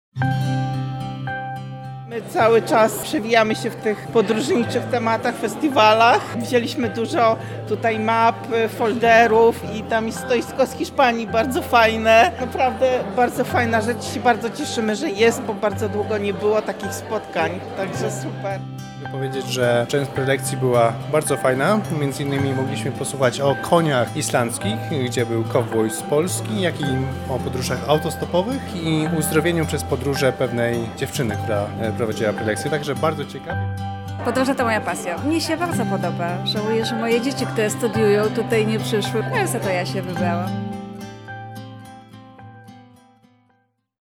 Widzowie, którzy przybyli na Festiwal, chętnie podzielili się swoimi wrażeniami z naszym reporterem: